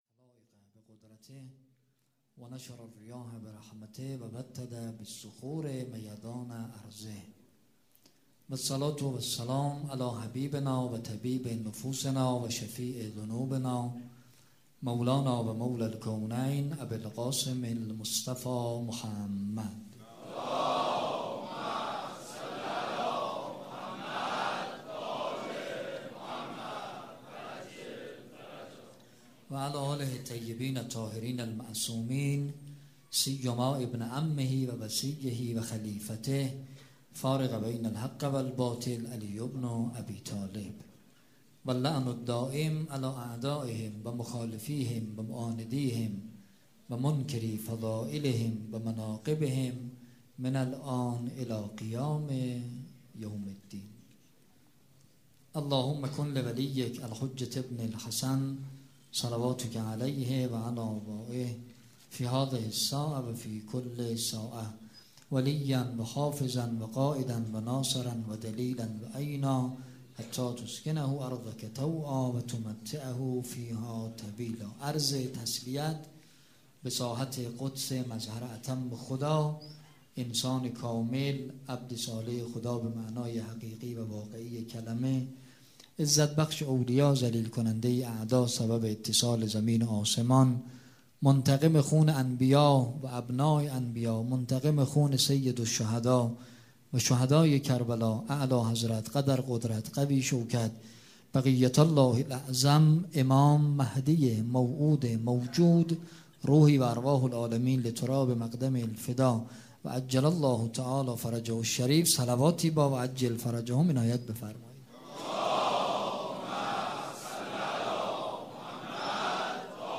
دوم صفر 95 - ريحانة الحسين - سخنرانی